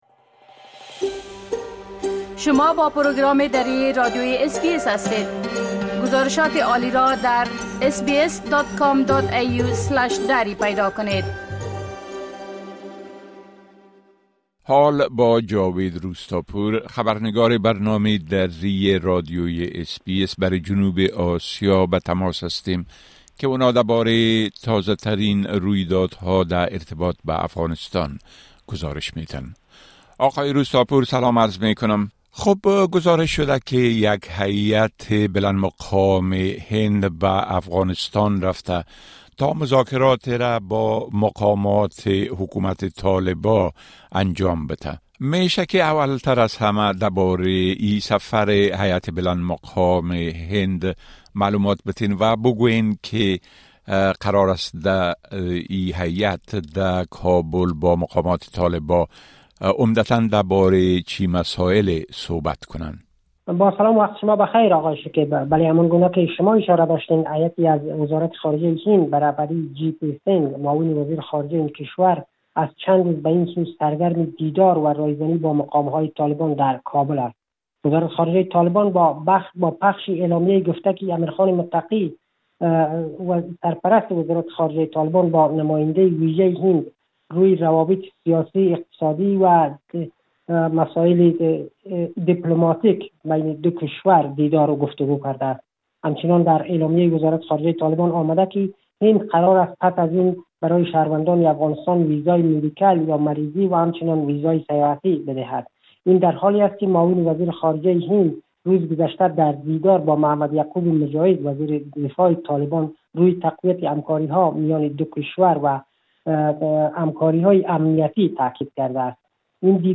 خبرنگار ما برای جنوب آسیا: يك هېأت هندى در كابل با مقامات طالبان دربارۀ توسعۀ مناسبات مذاكره كرده است
گزارش كامل خبرنگار ما، به شمول اوضاع امنيتى و تحولات مهم ديگر در افغانستان را در اين‌جا شنيده مى توانيد.